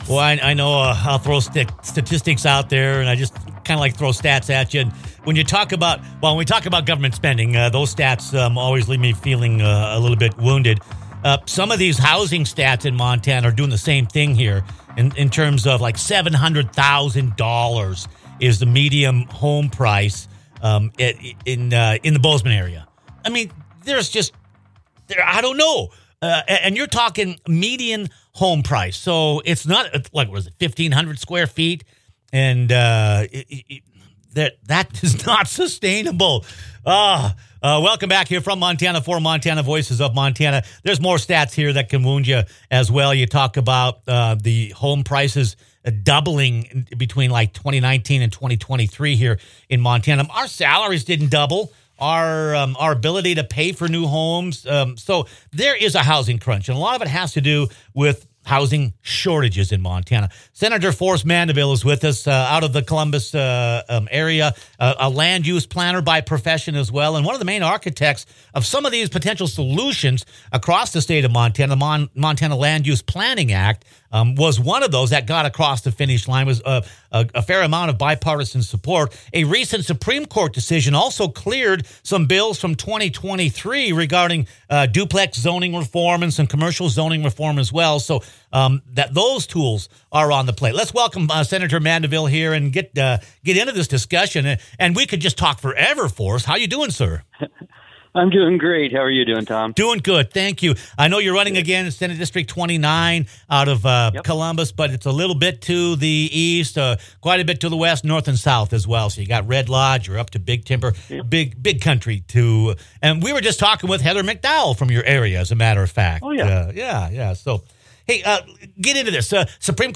Montana’s housing shortage has driven prices to record levels—but new state laws aimed at removing development barriers may be starting to make a difference. click on the podcast as land-use planner and State Senator Forrest Mandeville discusses the housing reform package sometimes called the “Montana Miracle,” a recent Supreme Court decision that cleared the way